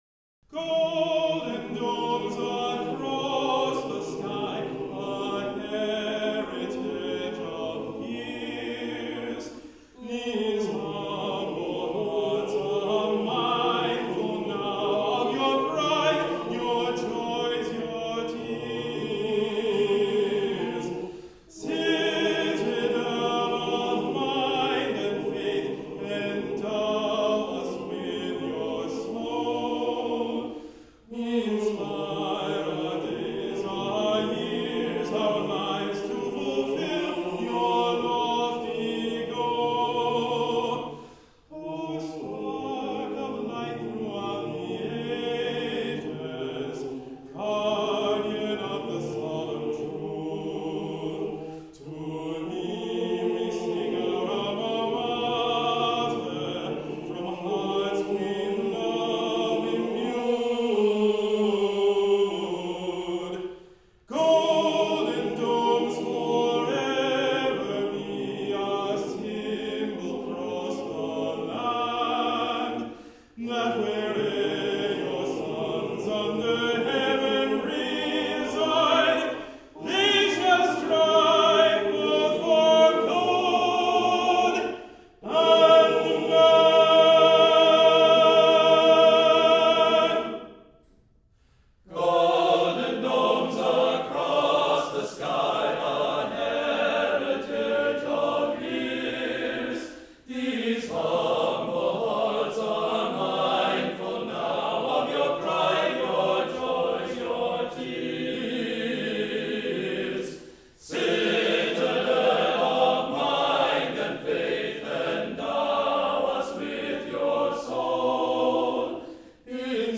UPDATE (10/31/05): I got my hands on a copy of a recording of the song, performed in September 2005.
It expresses the credo of Yeshiva College set to Choral music.